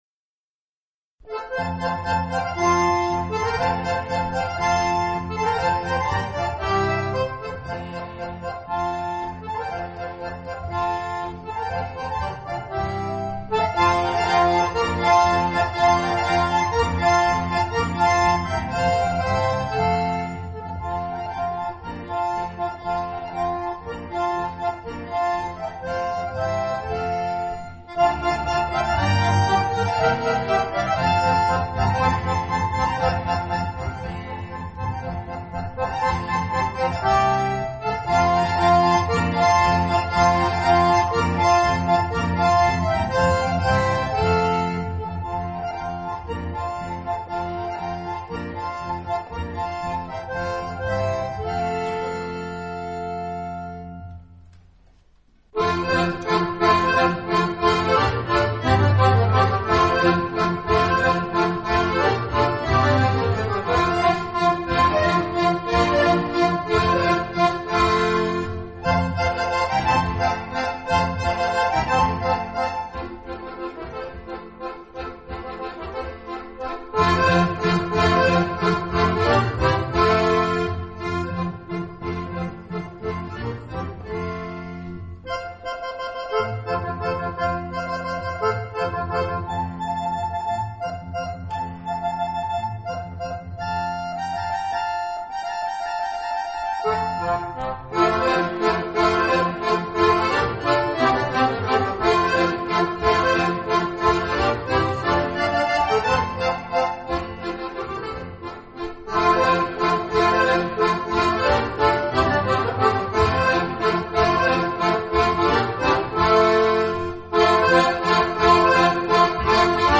2004 – Akkordeonorchester Neustadt bei Coburg e. V.